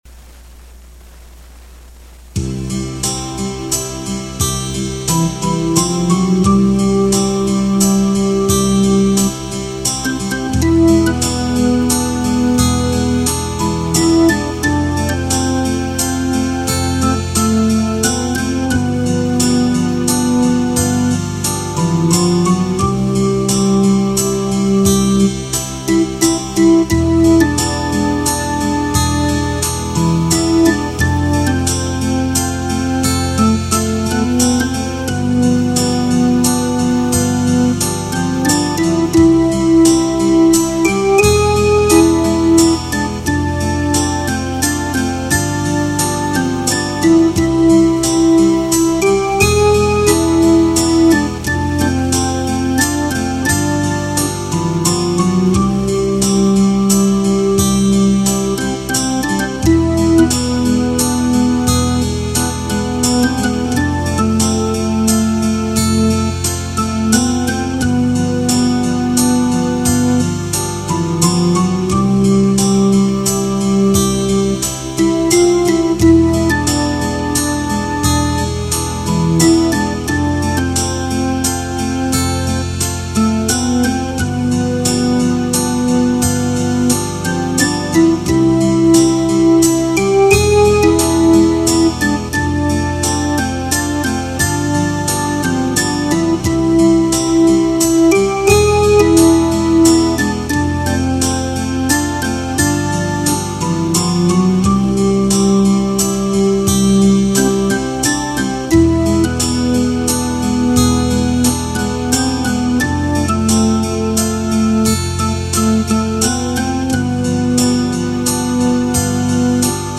Le TRK est le fichier midi en format mp3 sans la mélodie.